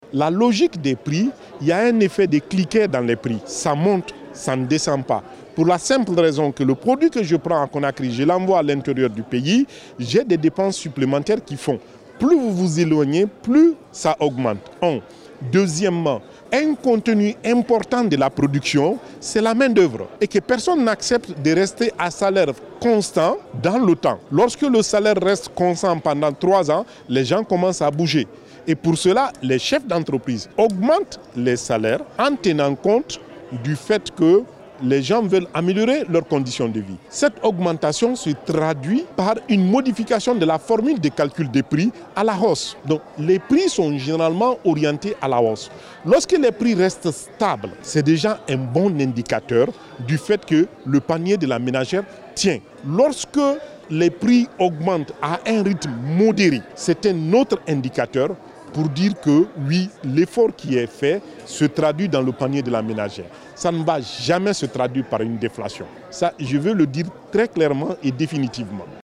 Selon le document budgétaire présenté en séance plénière ce lundi, 11 septembre 2023 au CNT, les prévisions budgétaires s’élèvent à près de 30 milles milliards de francs guinéens contre un montant d’un peu plus 27 milles milliards GNF en loi des finances initiale 2023, soit une augmentation de 4,01%.